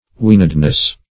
Weanedness \Wean"ed*ness\, n. Quality or state of being weaned.